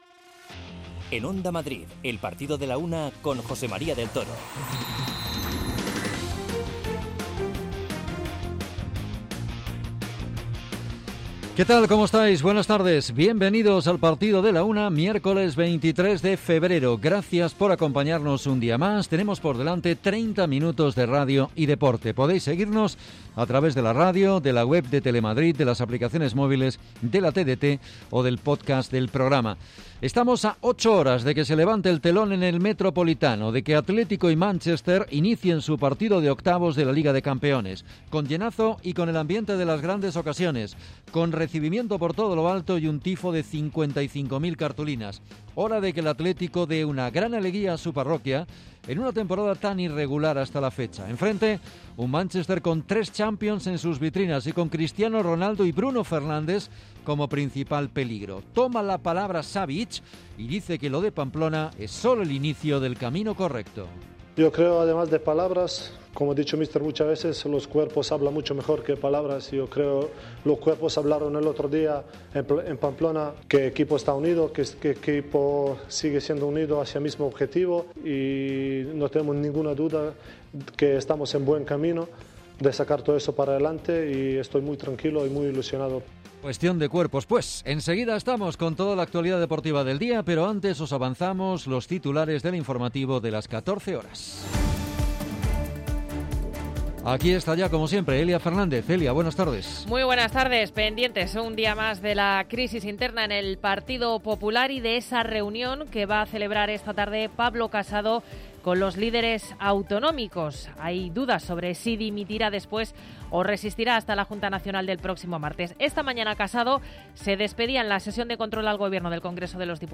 Es la referencia diaria de la actualidad deportiva local, regional, nacional e internacional. El rigor en la información y el análisis medido de los contenidos, con entrevistas, reportajes, conexiones en directo y el repaso a la agenda polideportiva de cada día, son la esencia de este programa.